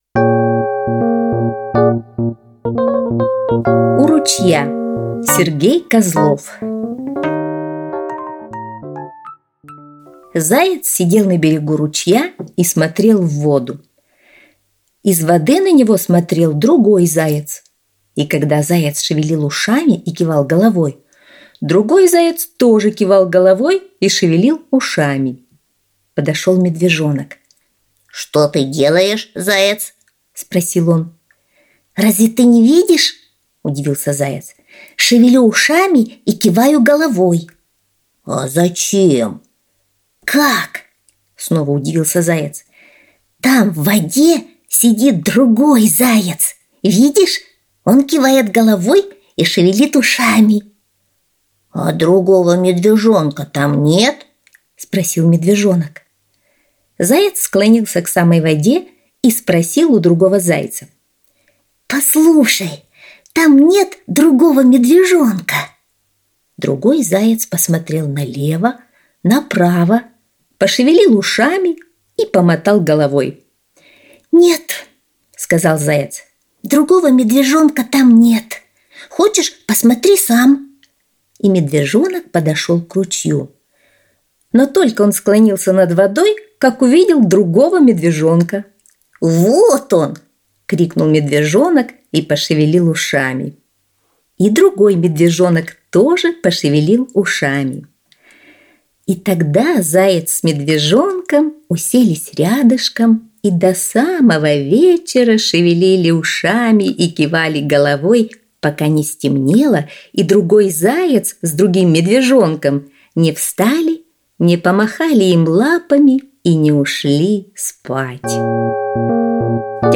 У ручья – Козлов С.Г. (аудиоверсия)
Аудиокнига в разделах